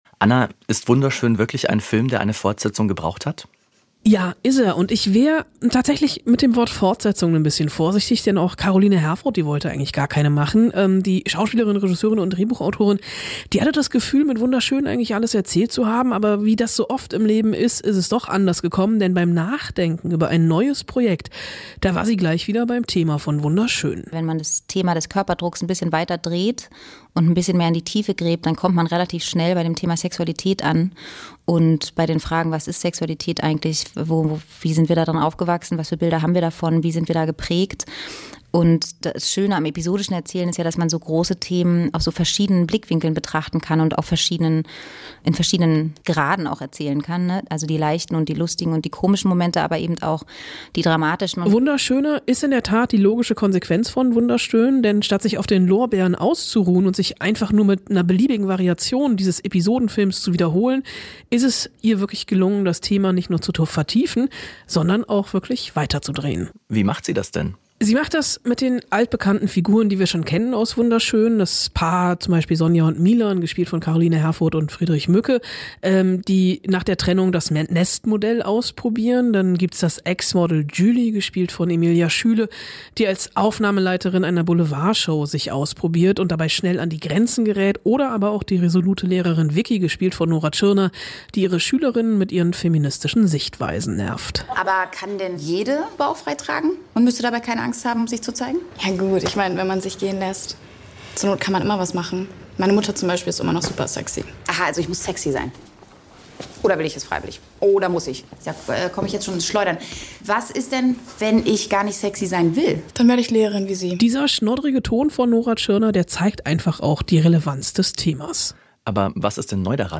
Topthemen aus Politik, Wirtschaft, Wissenschaft und Sport: Wir ordnen ein, wir klären auf, wir bohren nach. "SWR Aktuell Im Gespräch" - das sind Interviews mit Menschen, die etwas zu sagen haben.